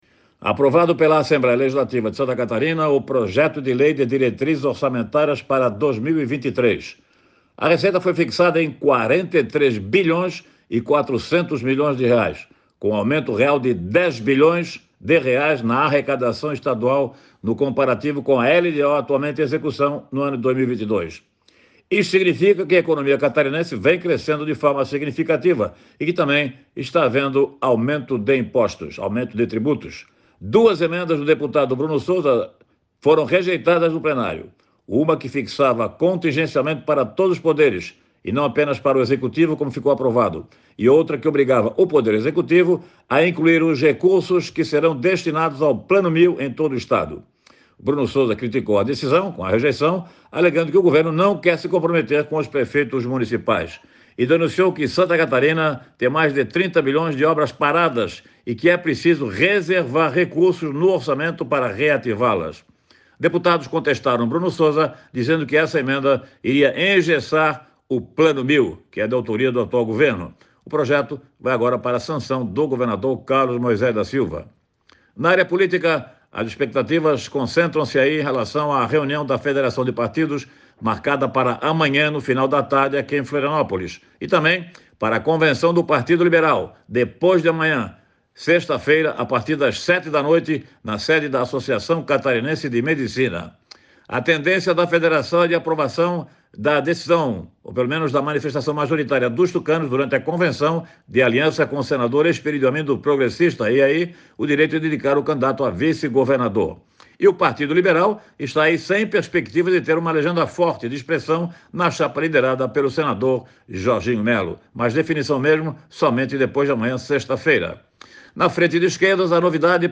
Jornalista destaca as definições de candidatos na federação de partidos na quinta (4) e do PL na sexta (5)